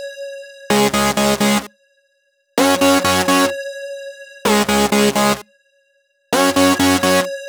VEE Melody Kits 05 128 BPM Root C#.wav